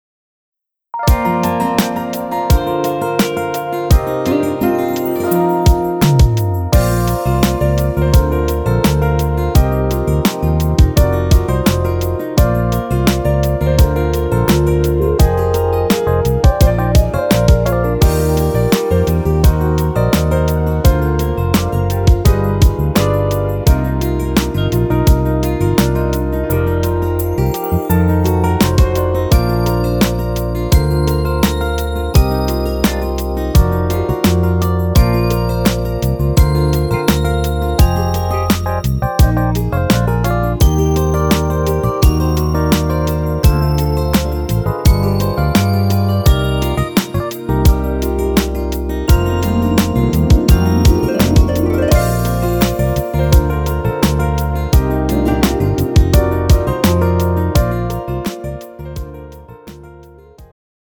음정 남자-1키
장르 축가 구분 Pro MR